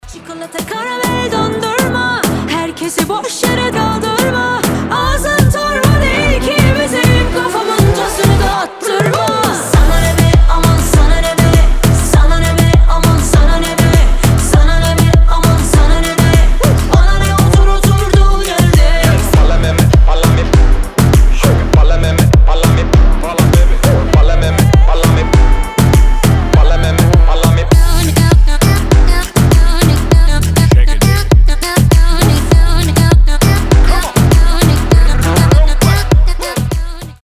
• Качество: 320, Stereo
громкие
заводные
Dance Pop
восточные
Moombahton
красивый женский вокал